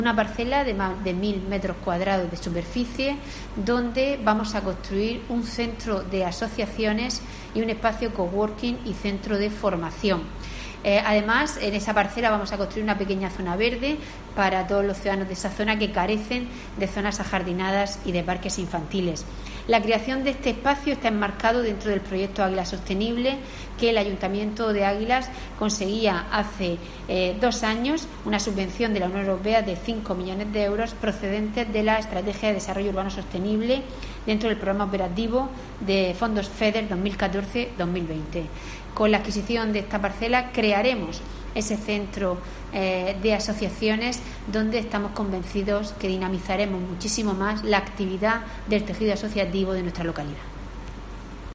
Maria del Carmen Moreno, alcaldesa de Águilas sobre parcela